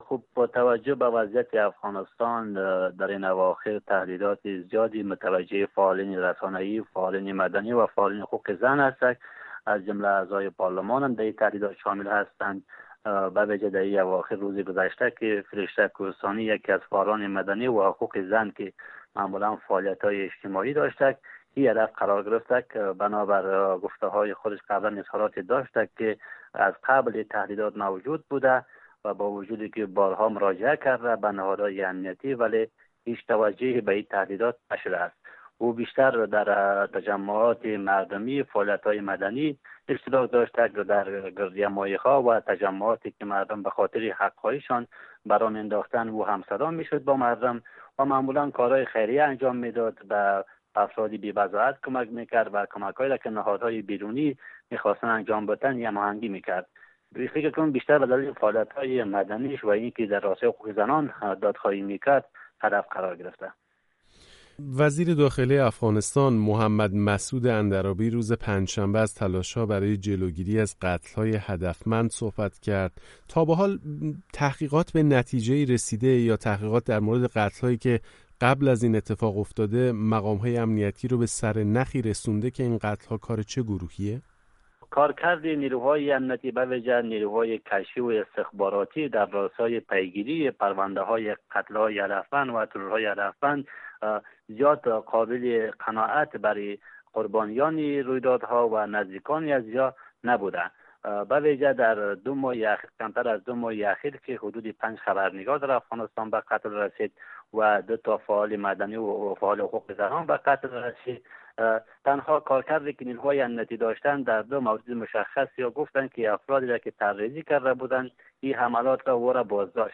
در گفت و گویی که با رادیو فردا داشته